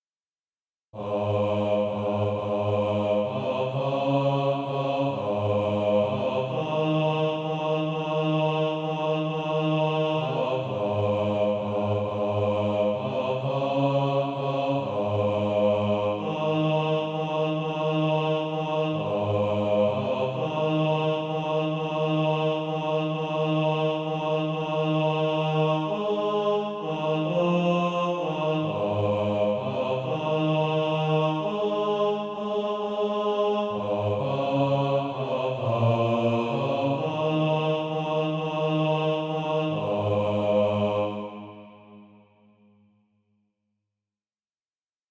(SATB) Author
Bass Track.
Practice then with the Chord quietly in the background.